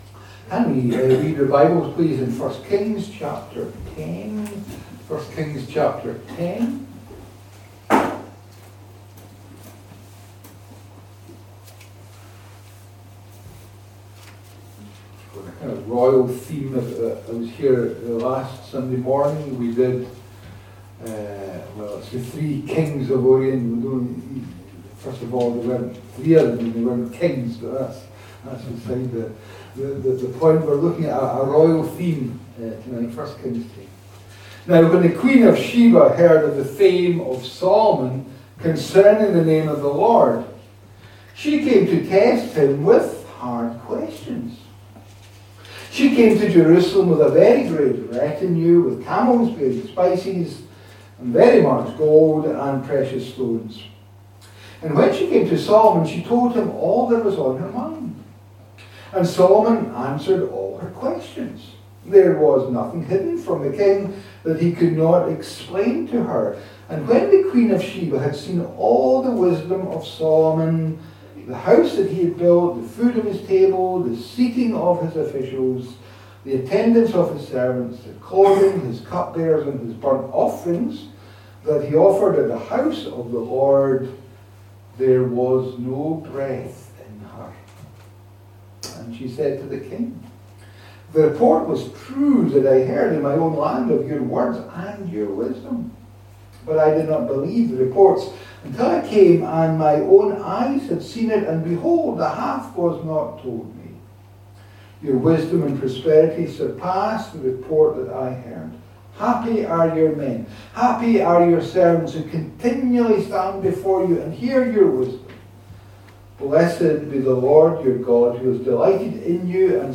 A link to the video recording of the 6:00pm service, and an audio recording of the sermon.
Series: Individual sermons